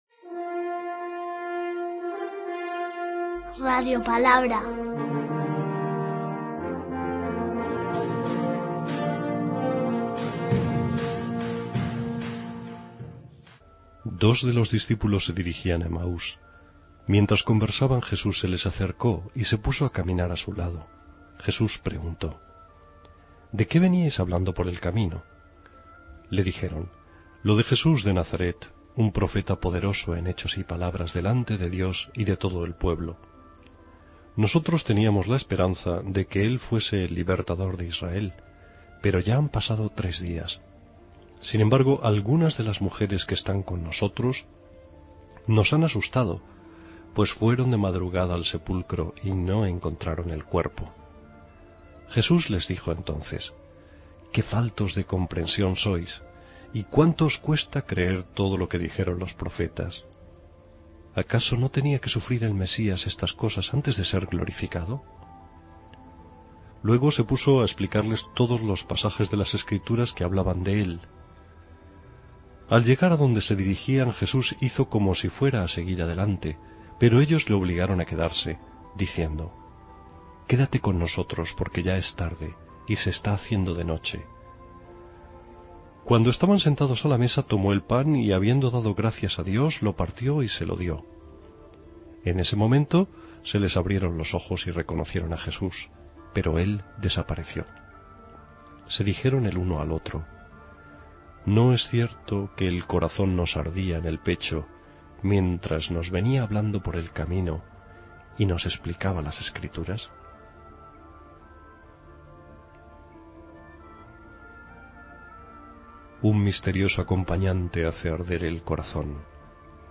Lectura del santo evangelio de hoy según San Lucas 24,13-35